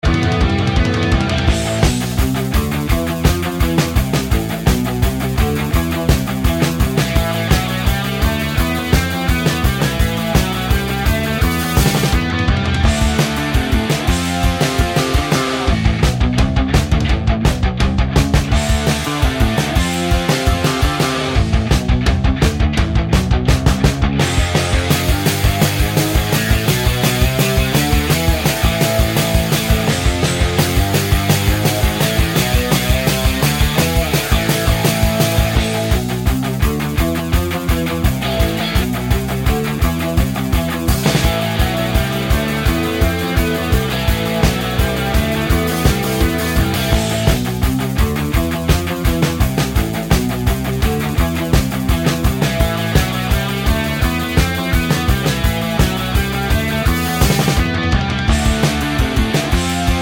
no Backing Vocals Punk 2:34 Buy £1.50